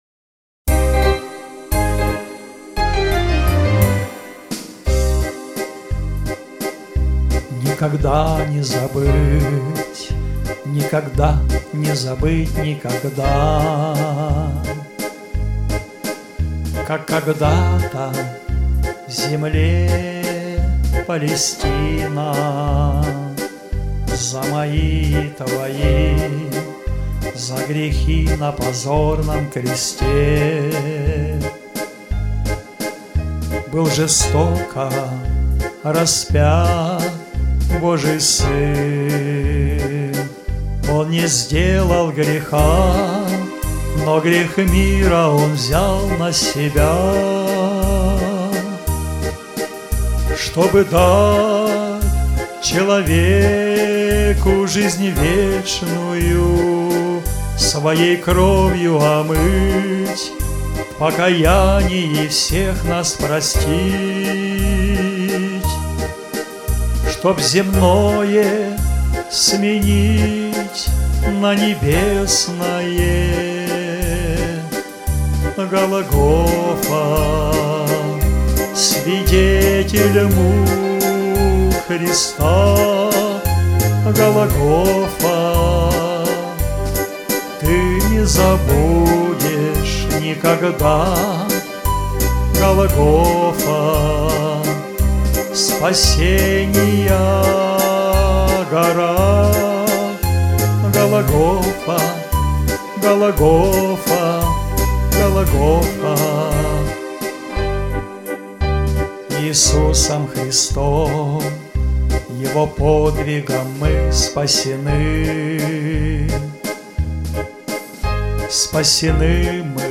Христианские песни